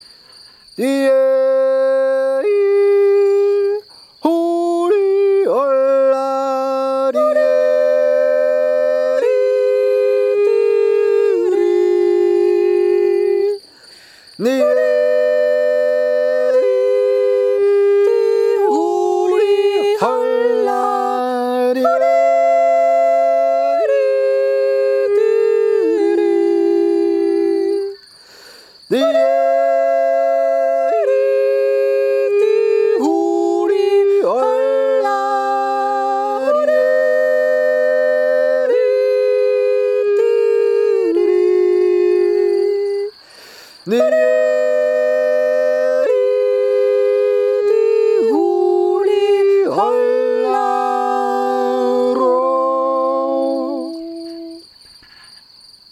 Unsere Jodler
Viele Aufnahmen sind Jodelmomente, die spontan festgehalten sind (Feldaufnahmen). So klingt es, wenn zwei oder drei Menschen zusammenkommen und einen Jodler tun.
Feldaufnahme